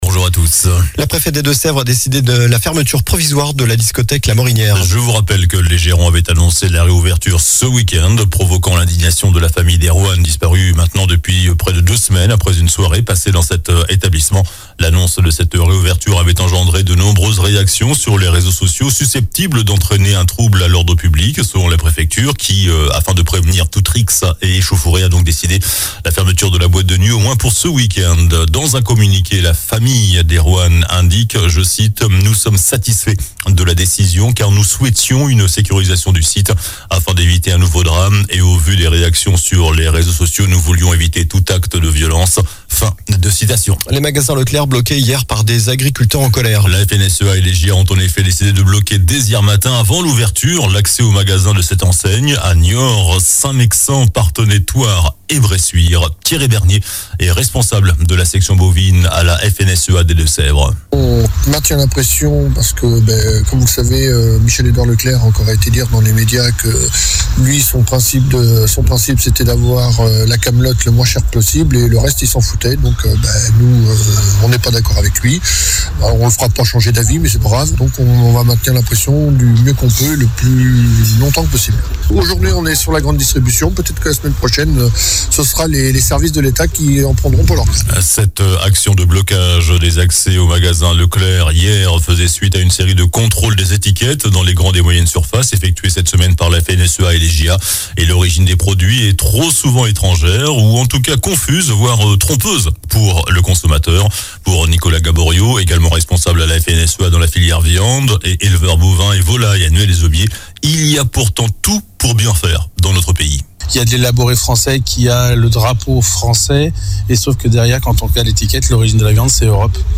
JOURNAL DU SAMEDI 24 FEVRIER